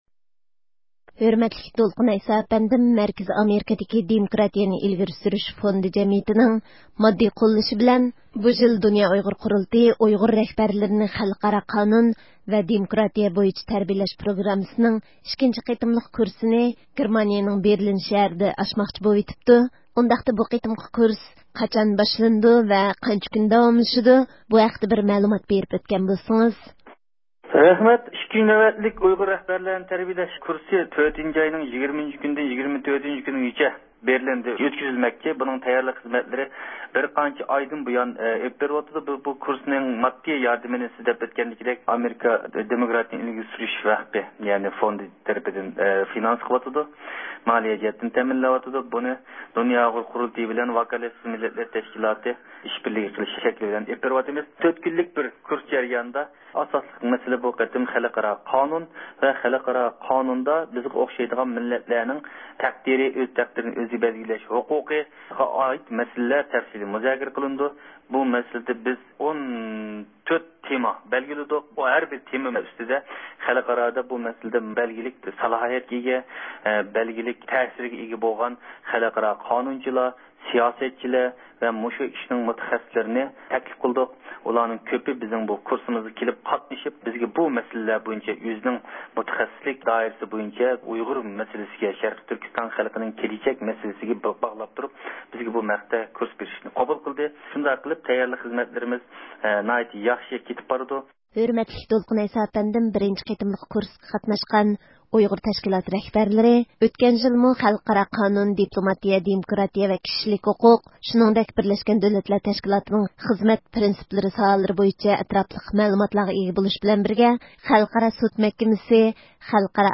بىز ئىككىنچى قېتىملىق كۇرسىنىڭ خىزمەت تەييارلىقلىرى ۋە باشقا مۇناسىۋەتلىك مەسىلىلەر ھەققىدە رادىئو ئاڭلىغۇچىلارغا ئالدىن ئۇچۇر بېرىش ئۈچۈن، د ئۇ ق نىڭ باش كاتىپى دولقۇن ئەيسا ئەپەندىنى زىيارەت قىلىشنى مۇۋاپىق كۆردۇق.